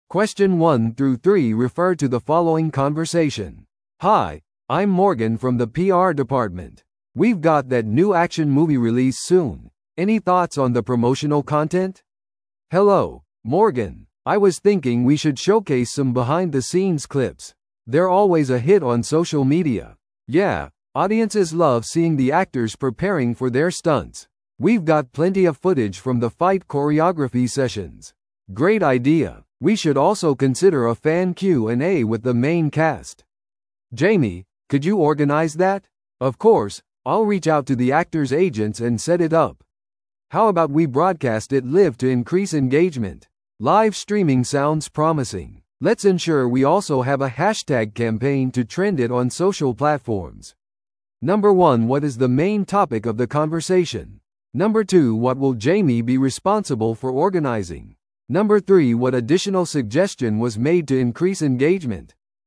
TOEICⓇ対策 Part 3｜映画プロモーション戦略について – 音声付き No.229
No.1. What is the main topic of the conversation?